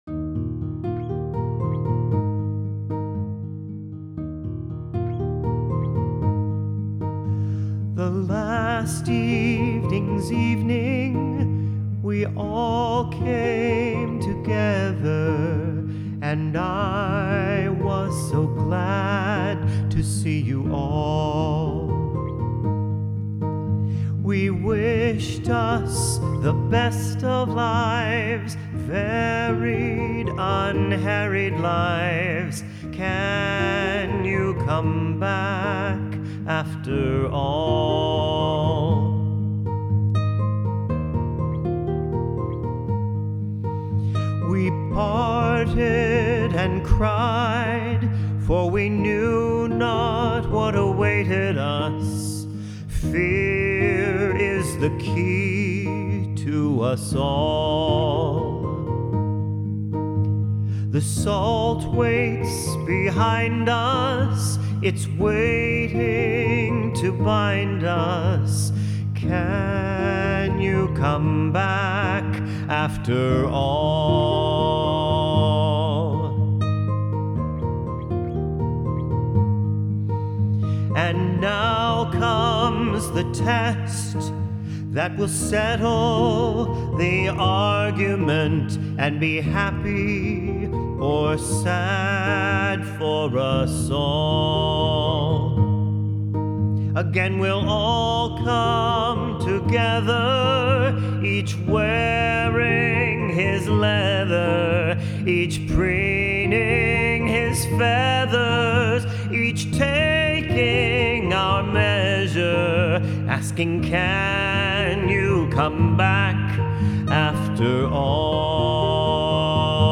Can You Come Back After All? (Vocals